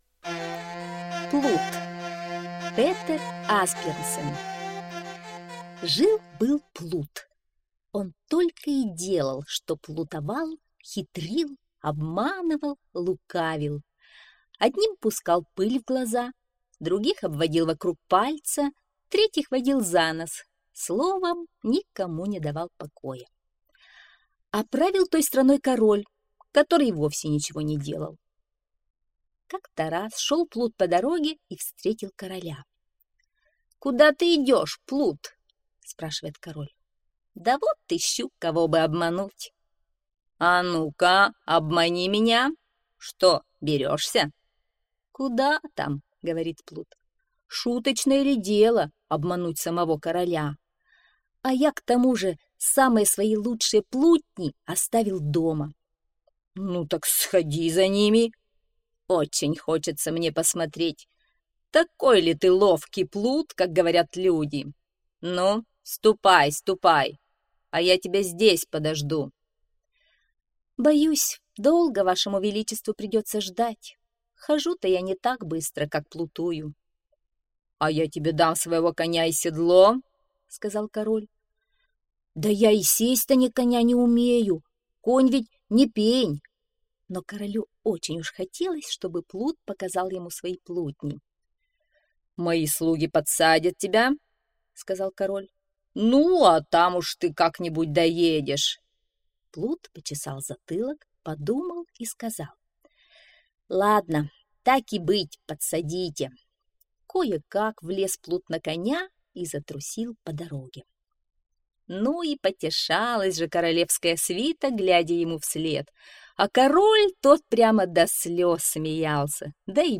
Плут - аудиосказка Асбьернсен - слушать онлайн